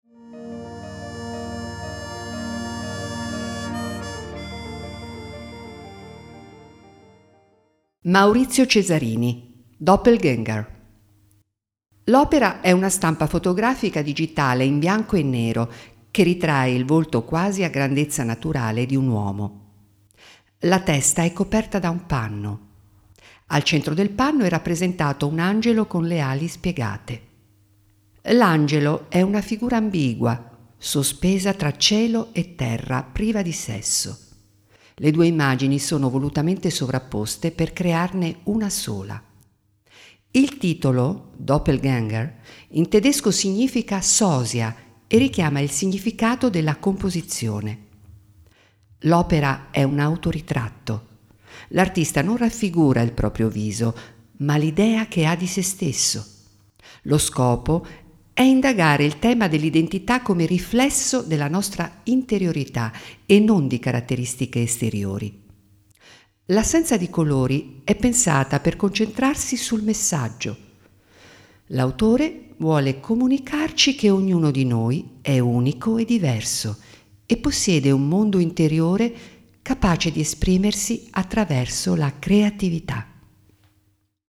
Audiodescrizione dell’opera